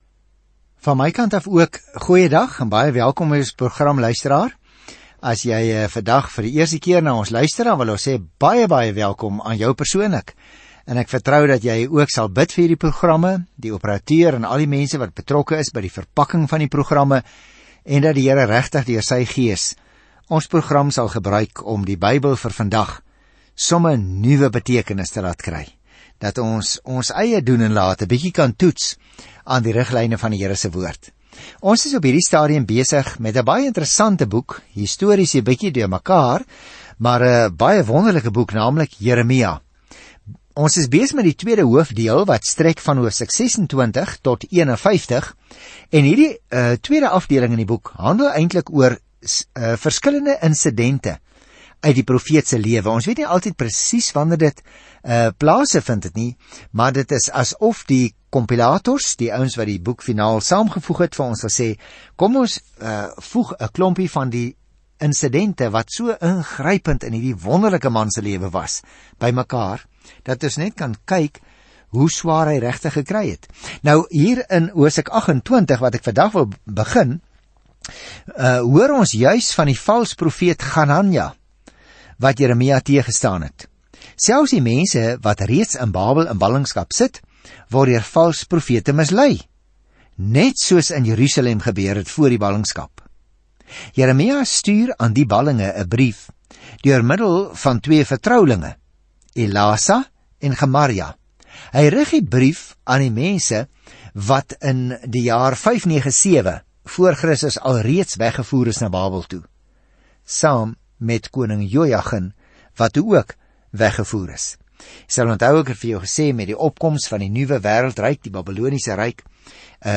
Skrif JEREMIA 31 Dag 19 Begin met hierdie leesplan Dag 21 Aangaande hierdie leesplan God het Jeremia, 'n teerhartige man, gekies om 'n harde boodskap te lewer, maar die mense ontvang die boodskap nie goed nie. Reis daagliks deur Jeremia terwyl jy na die oudiostudie luister en uitgesoekte verse uit God se woord lees.